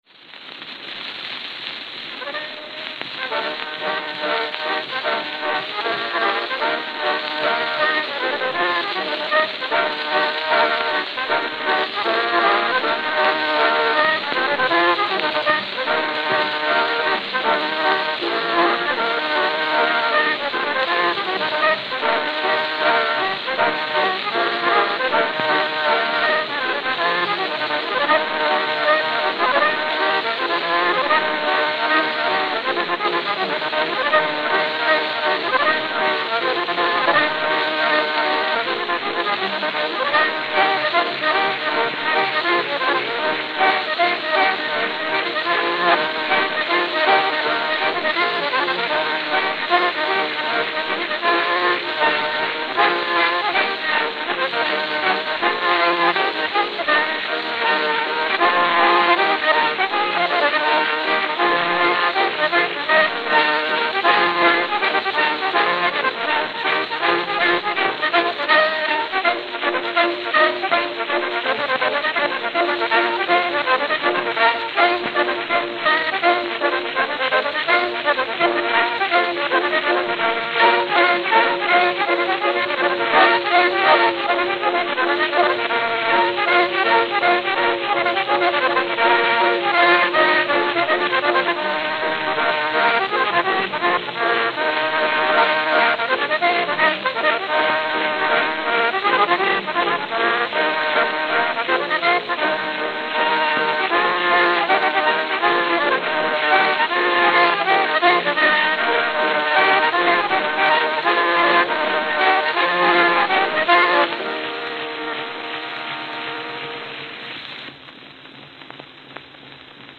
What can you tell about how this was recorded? CYLINDER RECORDS